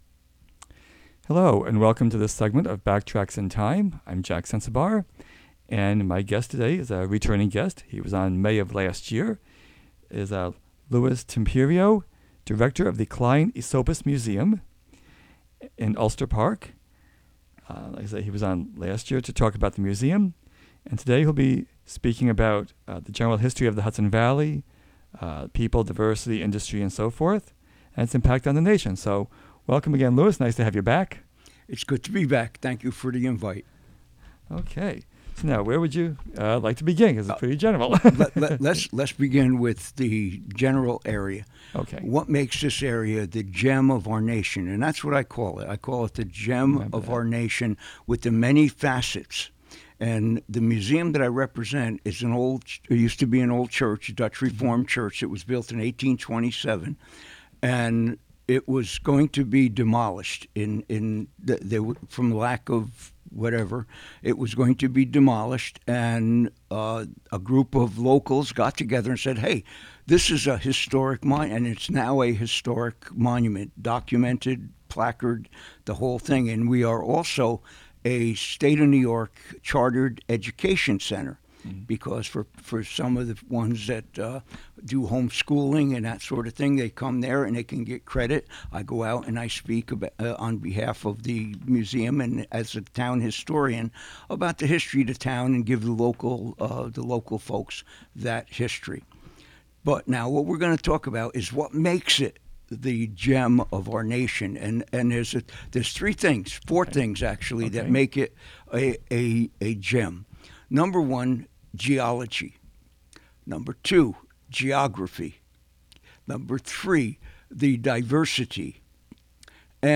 Dedicated to the history of Greene County, its notable people and places and the Hudson River. The show features interviews with local historians, longtime residents with stories to tell, and archival recorded material.